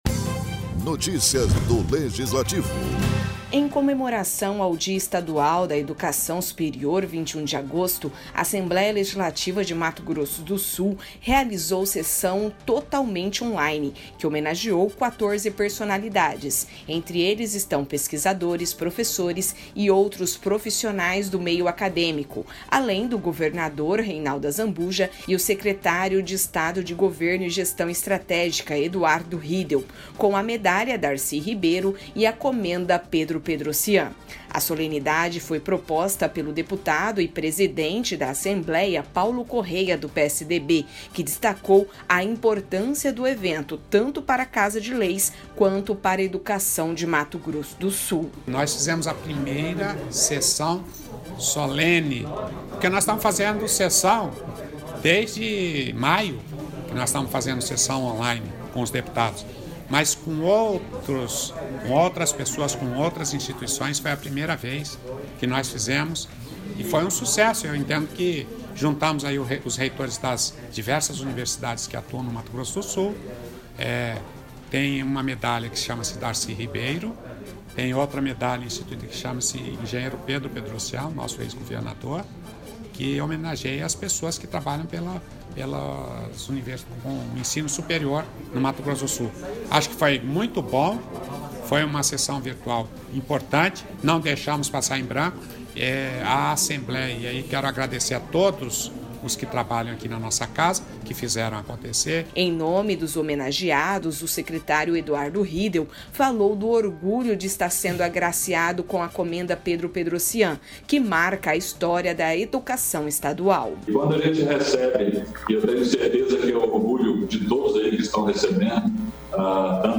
Locução e Produção